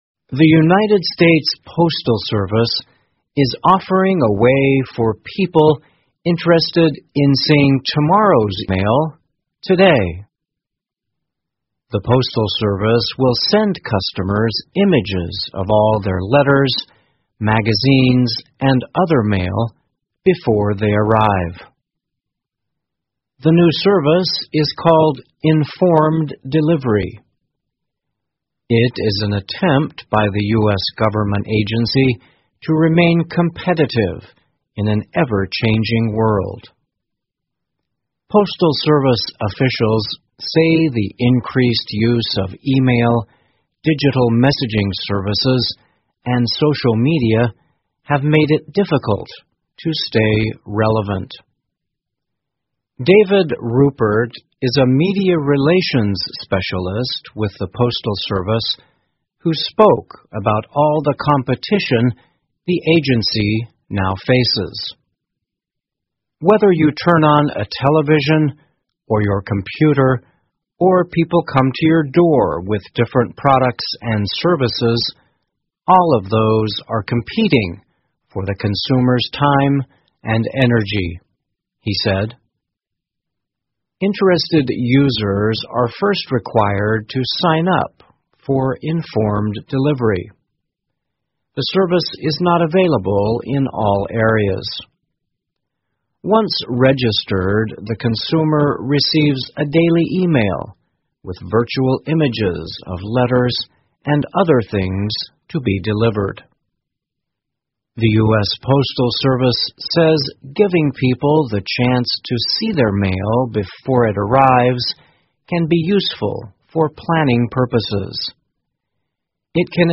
在线英语听力室US Mail Goes Digital, Seeks to Make Junk Mail Fun的听力文件下载,2018年慢速英语(二)月-在线英语听力室